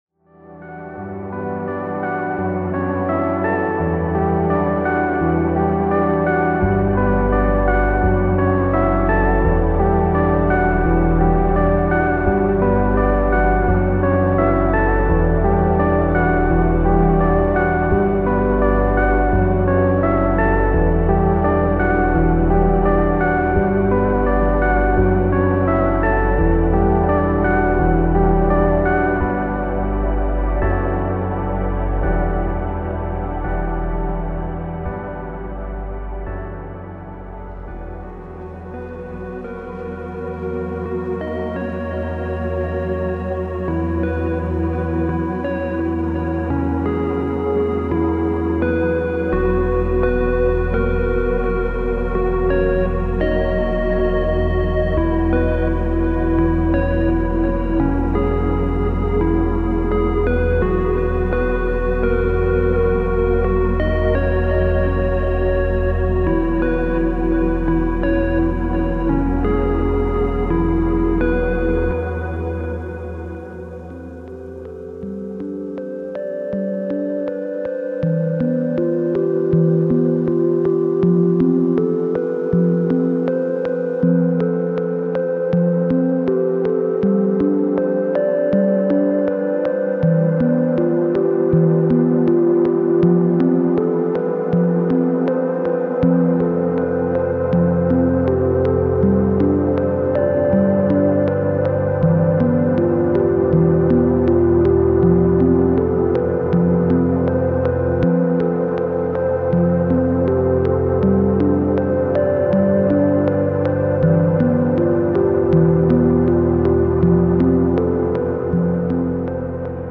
Genre:Ambient
微細で支えとなる感情的な足場として機能し、オーガニックな不完全さを伴いながら煌めき、伸び、減衰します。
一方でベースループは、重量感なく低音を支え、温かさと抑制を持って低域を響かせます。
45 Atmospheric Pad Loops